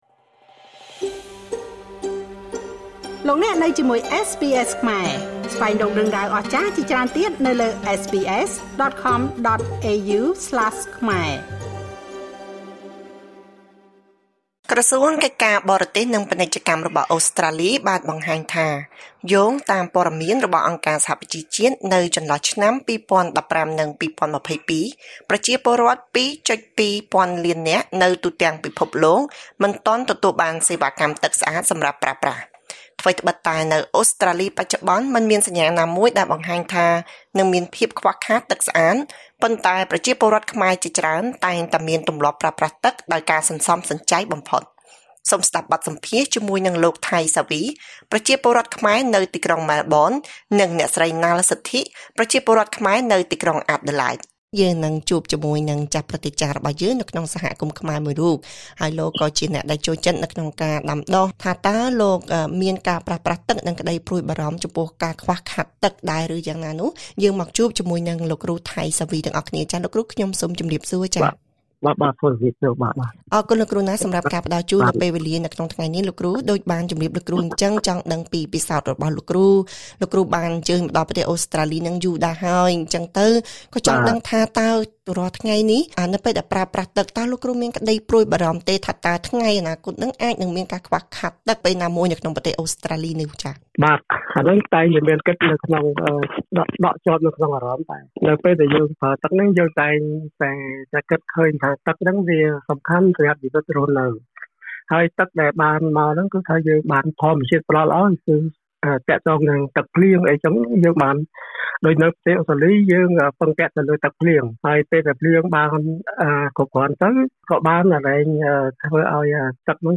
សូមស្តាប់បទសម្ភាសន៍ជាមួយលោក